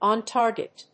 on tárget